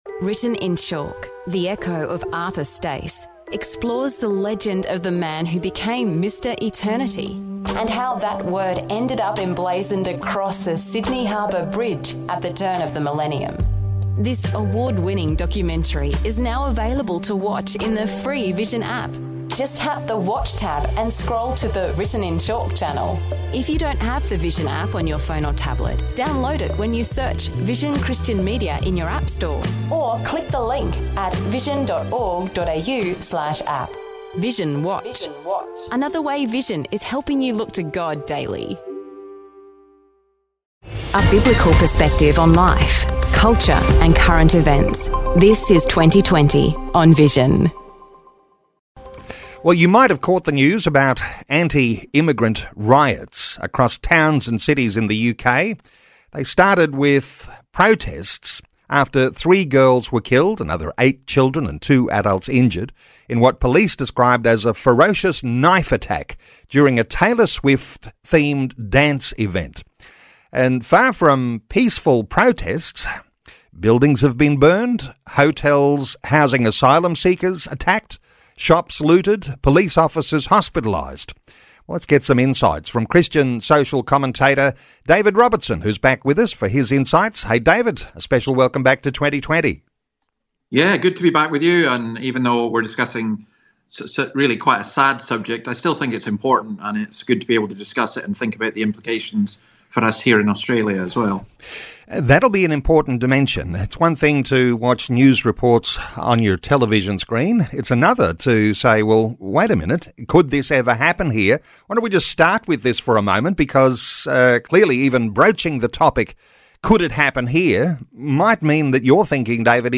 Interview on Vision Radio